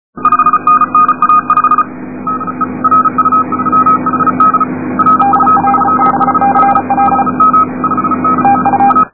Les fichiers à télécharger sont compressés au format MP3 à 1ko/sec, ce qui explique la très médiocre qualité du son.
World Wide DX en télégraphie sur décamétriques, dernier week-end de novembre.